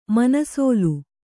♪ manasōlu